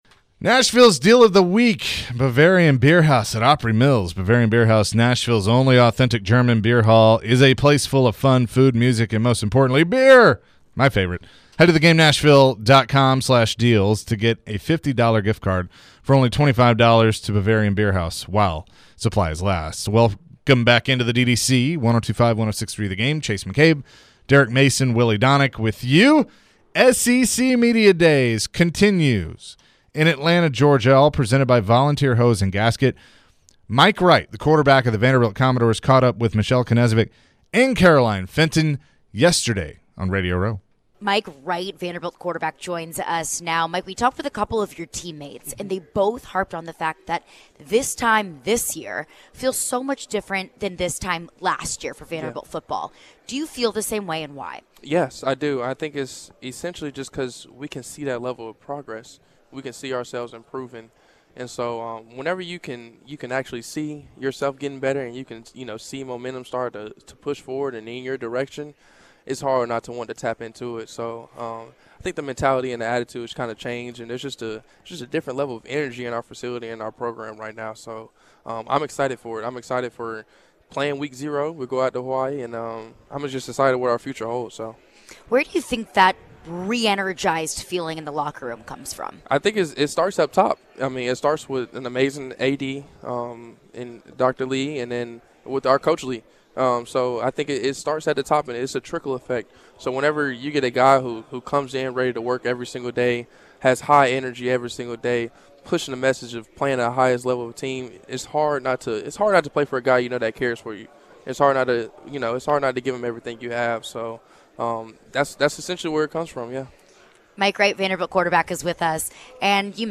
Interview (7-20-22)